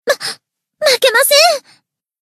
BA_V_Mimori_Battle_Damage_2.ogg